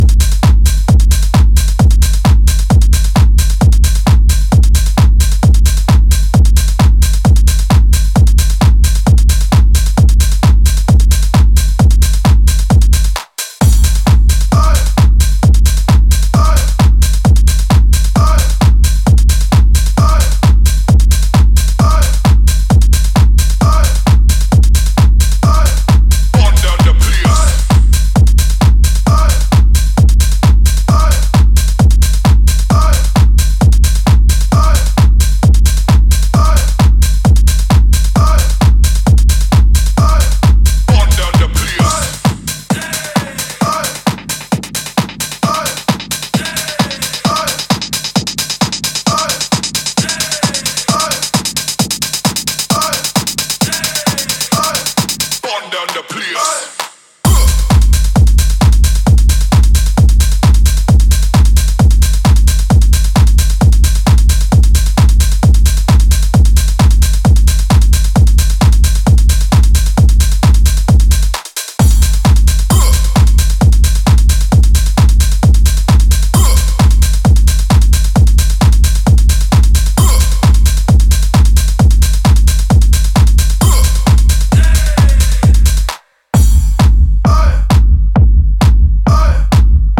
heavyweight techno number